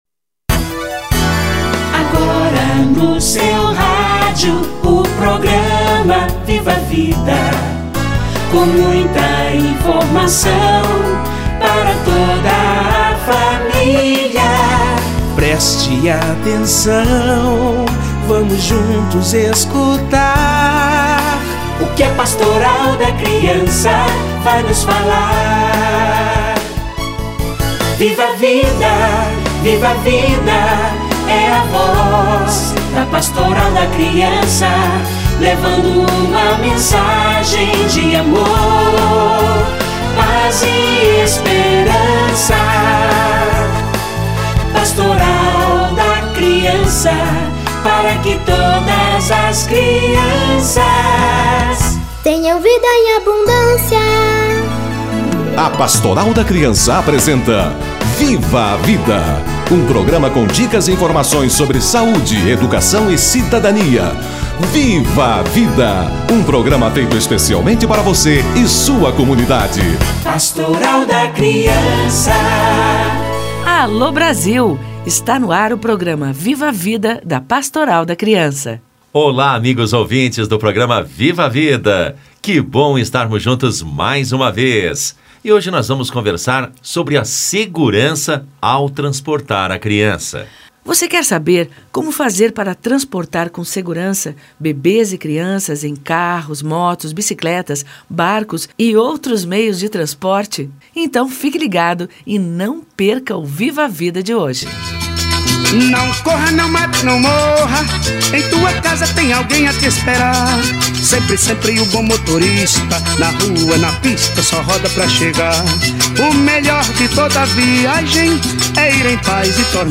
Segurança ao transportar a criança - Entrevista